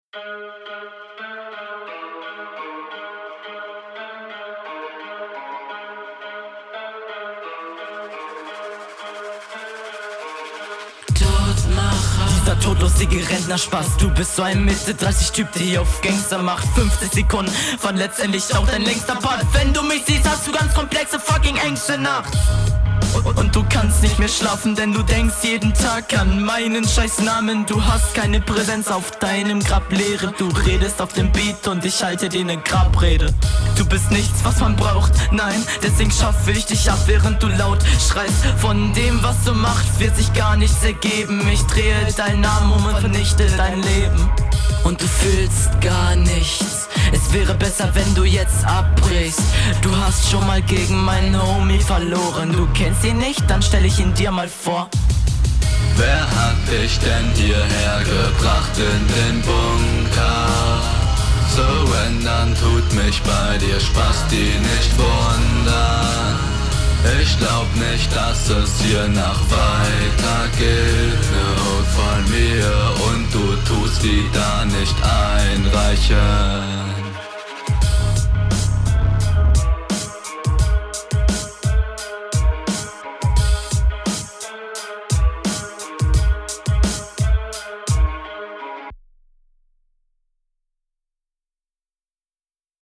Interessante Produktion, da ist einiges bearbeitet, leider finde ich die Experimente nicht gut umgesetzt.
Beat ganz cool. Sound Okay, geht aber noch besser.